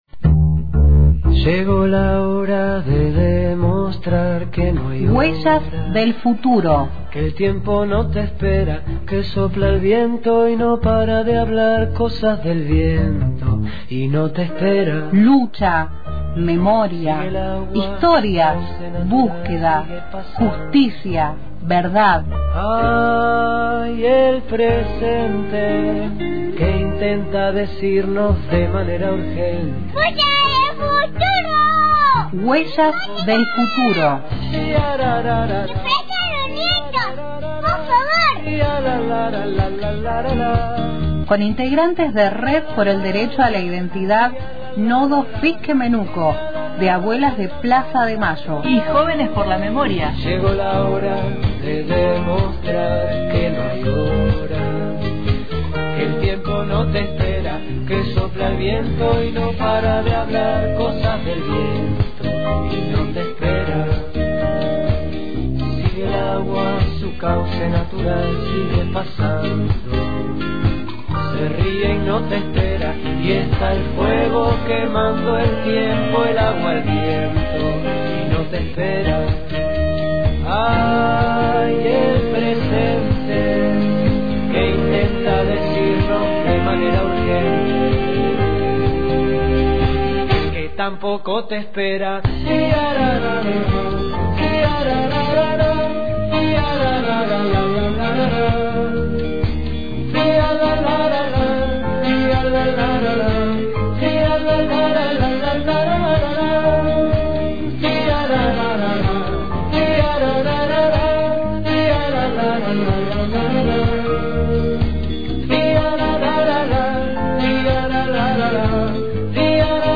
Comentamos sobre la campaña «Un Abrazo Para Abuelas» y escuchamos voces sobre cómo es abrazar a las abuelas.